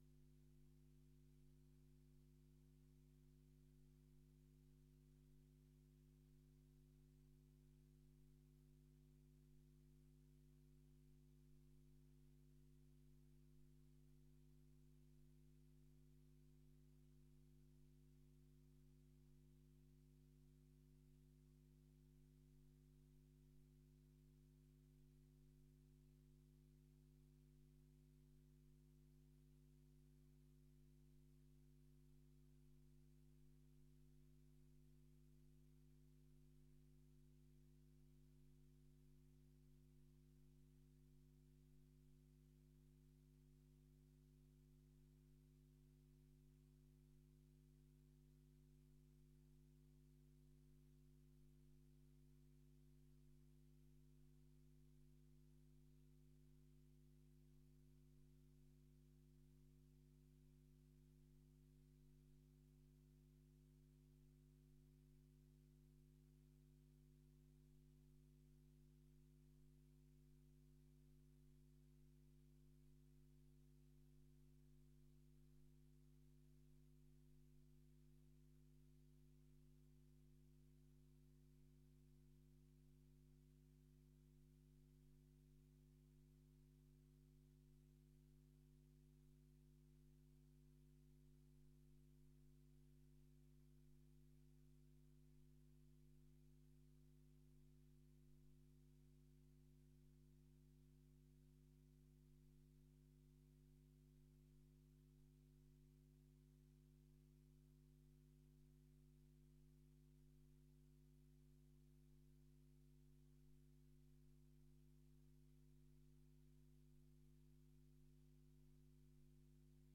Informatieve raadsvergadering 06 april 2023 20:00:00, Gemeente Diemen
Locatie: Raadzaal